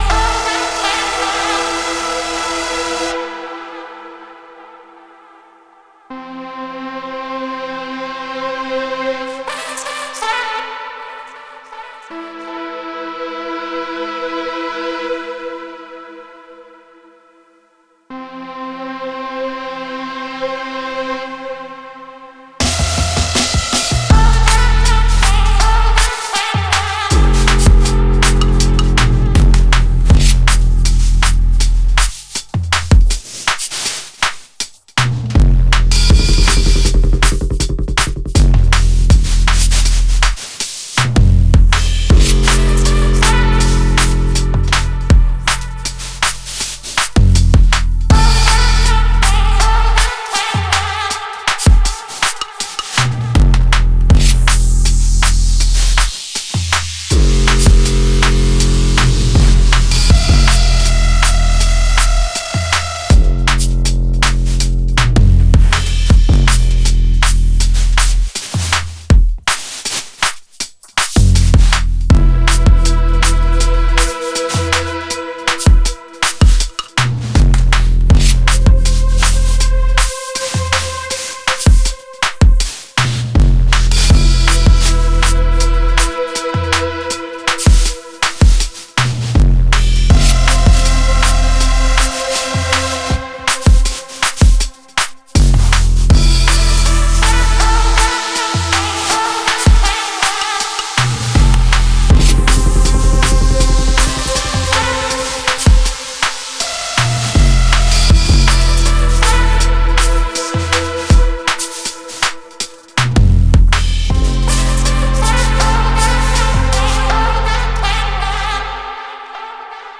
キャッチーなフックも満載のポスト・ベース最前線です。